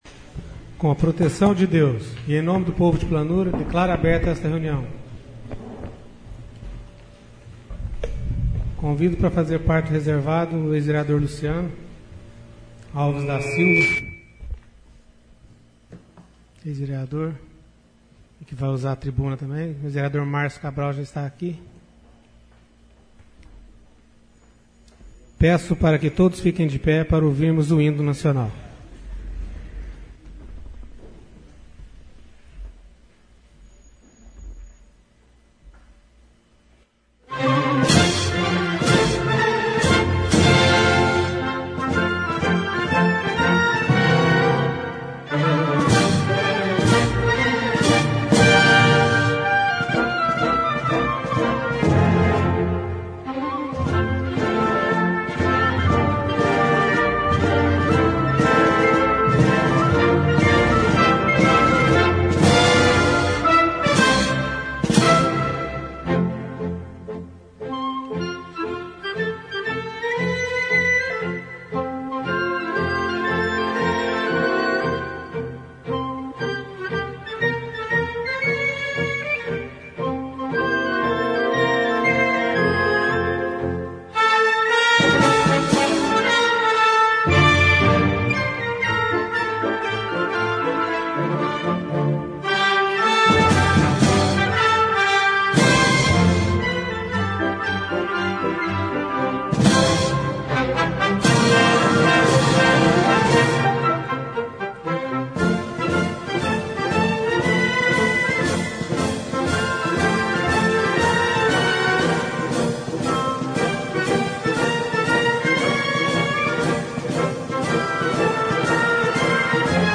Sessão Ordinária - 03/11/14